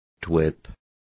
Complete with pronunciation of the translation of twerp.